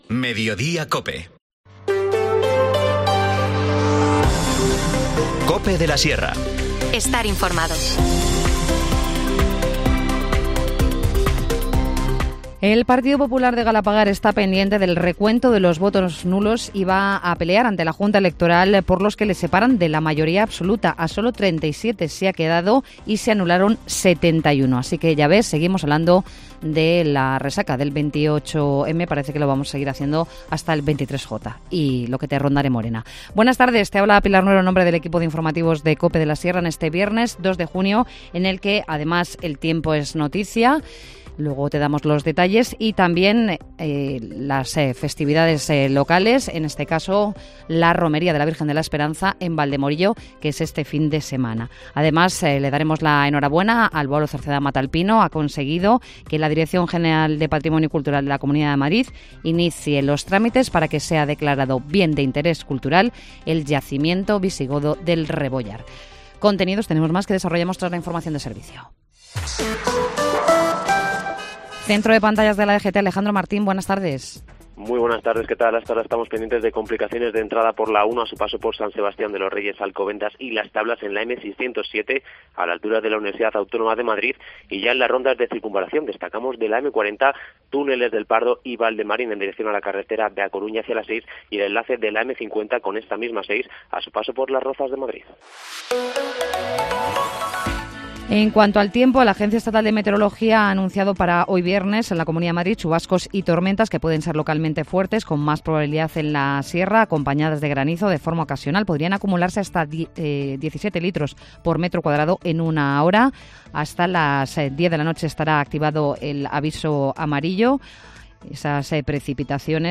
Informativo Mediodía 2 junio